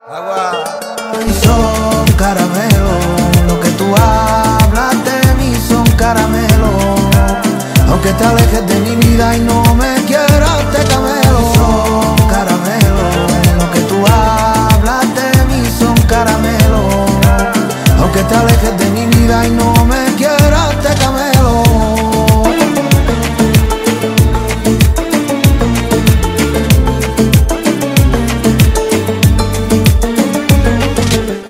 Siempre los últimos tonos de Reguetón